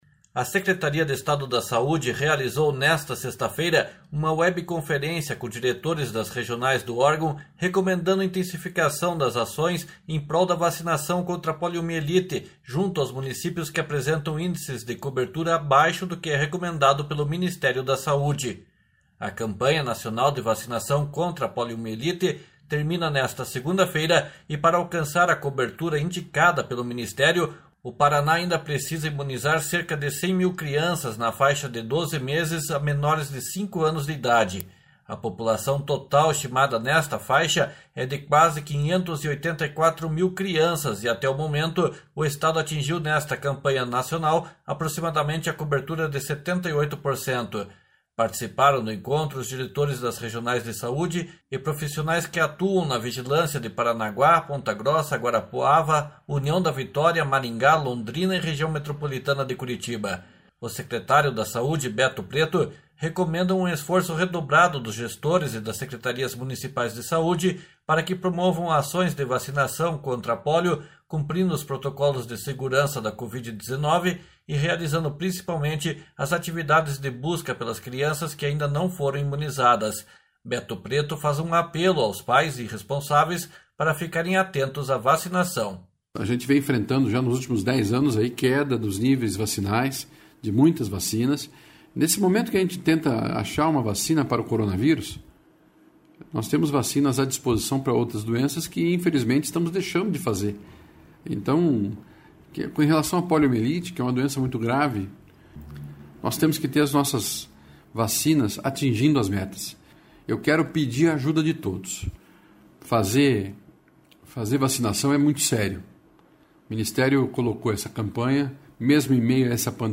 Beto Preto faz um apelo aos pais e responsáveis para ficarem atentos a vacinação. //SONORA BETO PRETO//